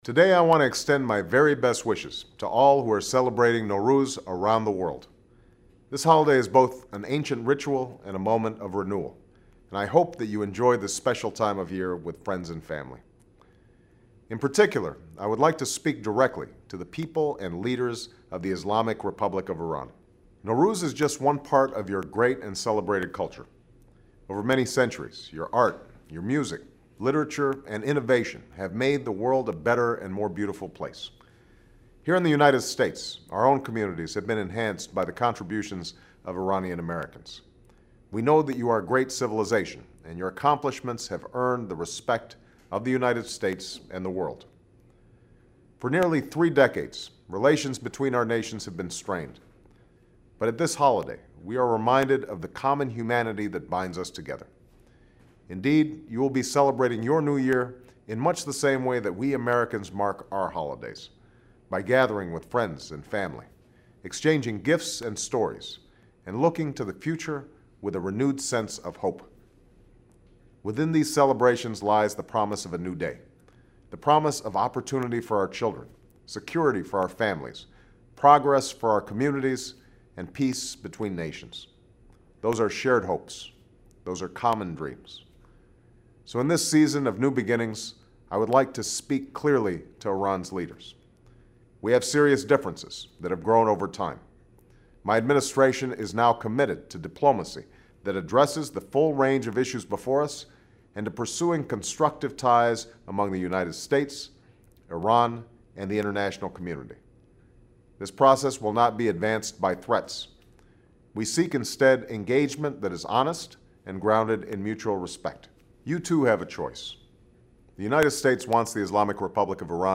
奥巴马3月19日特别电视讲话
VIDEOTAPED REMARKS BY THE PRESIDENT IN CELEBRATION OF NOWRUZ